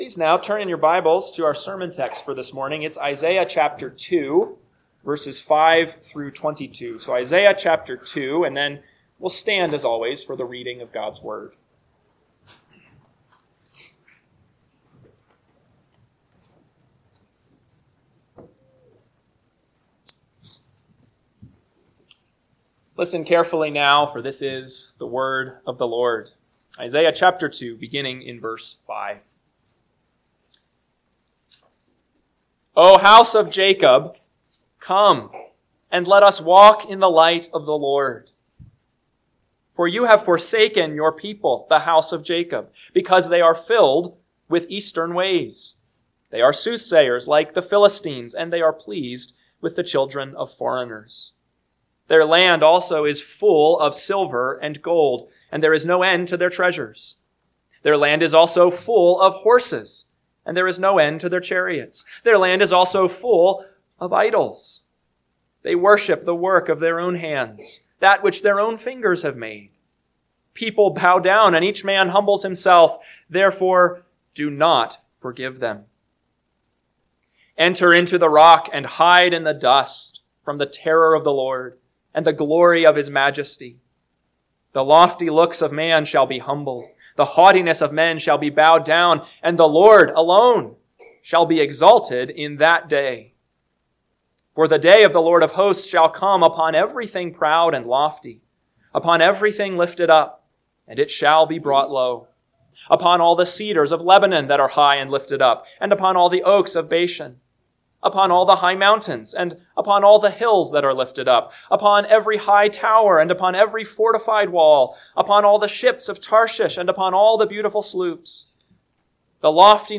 AM Sermon – 10/26/2025 – Isaiah 2:5-22 – Northwoods Sermons